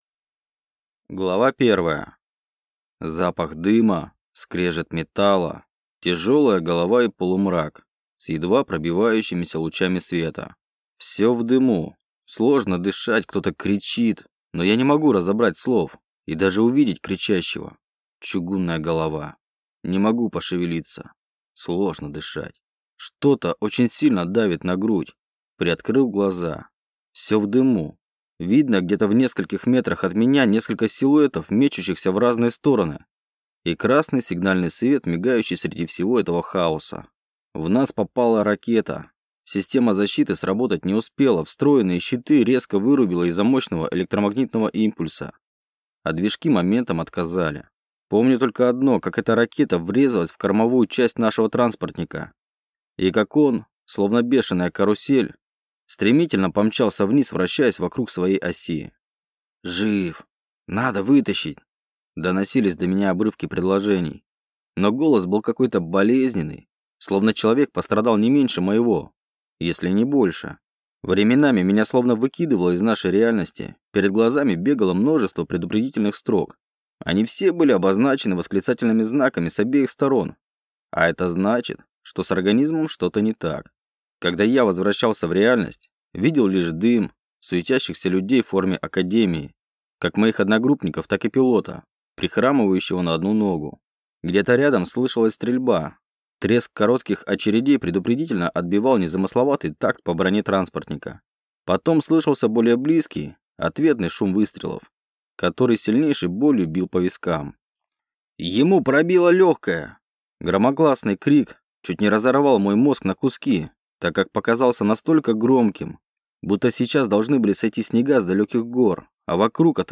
Аудиокнига Войд. Том 2 | Библиотека аудиокниг